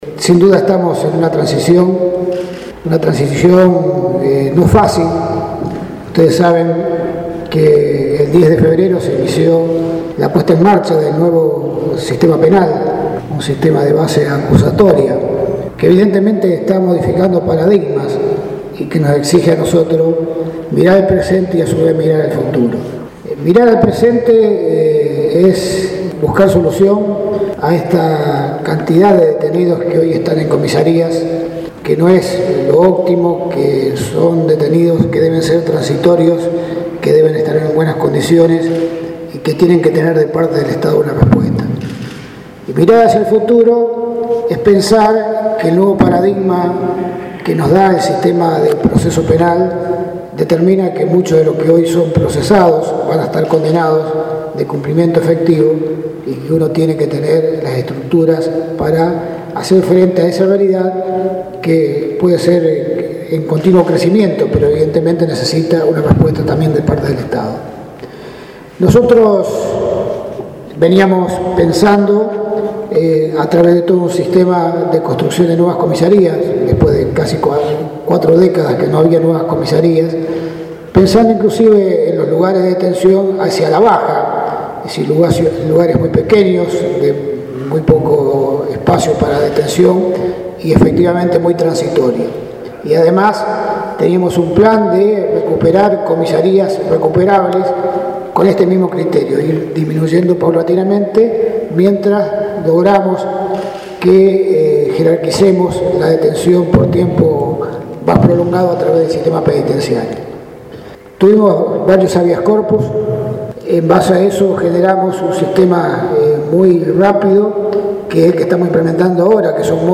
En su discurso, Lamberto destacó y reconoció el apoyo de la provincia de Buenos Aires, e indicó que el objetivo del proyecto es “ir disminuyendo los detenidos en comisarías”.
Del acto, desarrollado en el salón Blanco en la sede de Gobierno local, participaron, además, el ministro de Obras y Servicios Públicos, Julio Schneider; el secretario de Gobierno de Rosario, Fernando Asegurado, y los concejales locales, Diego Giuliano, y Miguel Cappiello, entre otros.